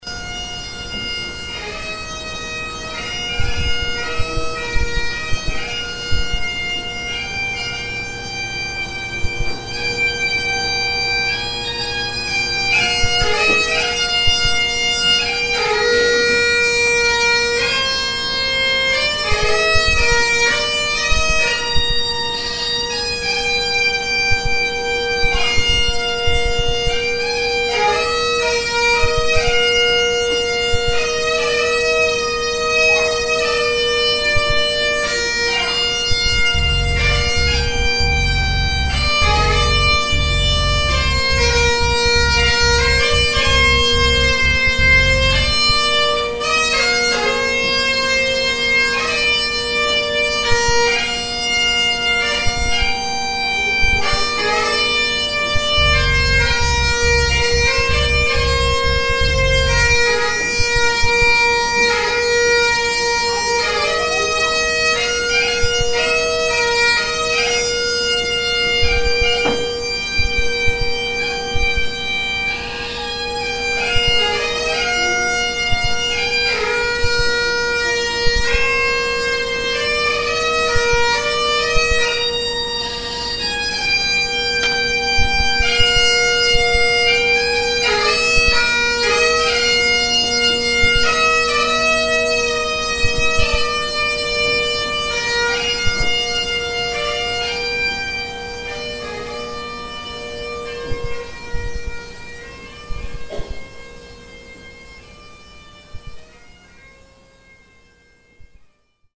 which ends as the piper walks into the distance and fades from view, signalling 11.00am and the start of our 2 minute silence. The bugler then struck up the Reveille, ending the silence.
piperlament.mp3